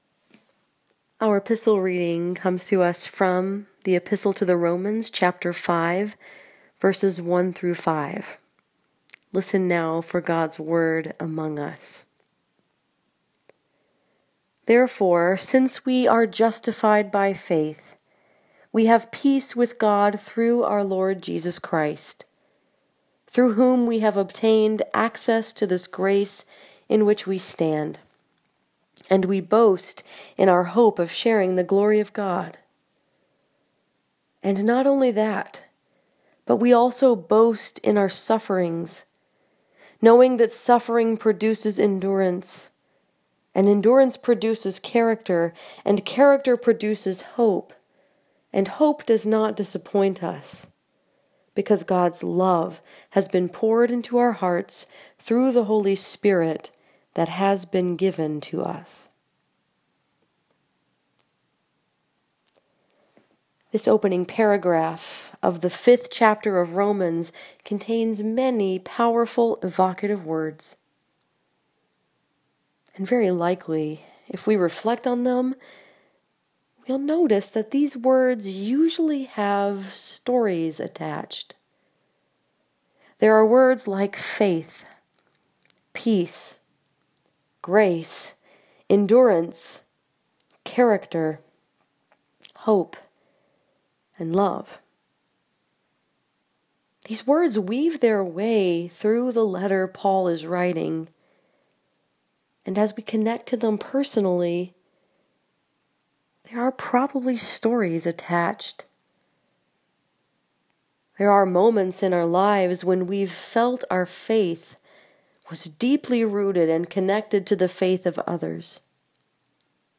This sermon was preached at St. Andrew’s Presbyterian Church in Dearborn Heights, Michigan and was focused upon Romans 5:1-5.